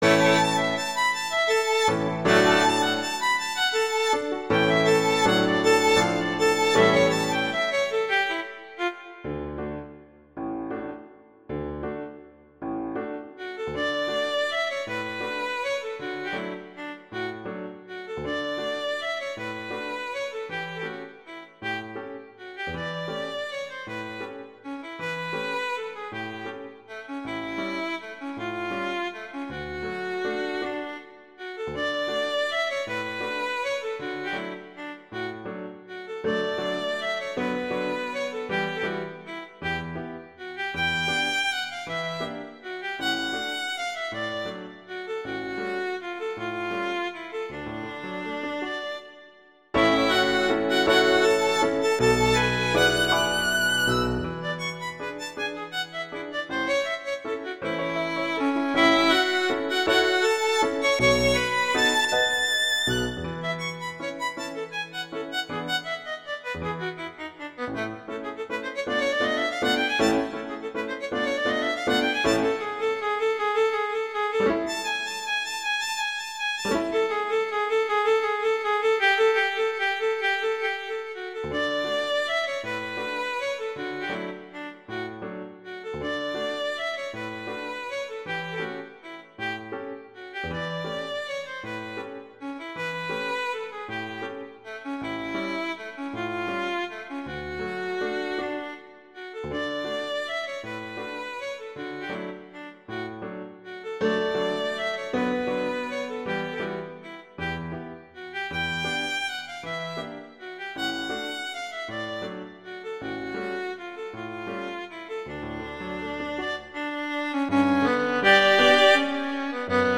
classical, wedding, festival, love
D major
♩=160 BPM
The melody is "classic waltz".